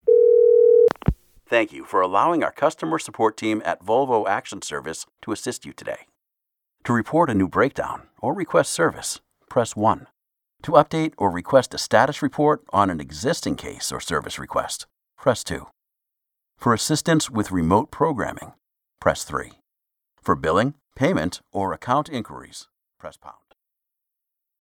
Mature Adult, Adult
The friendly, accessible, trustworthy, authoritative, “coffee-laced-with-caramel” voice of the nerdy, fun dad next door.
standard us
Volvo_IVR.mp3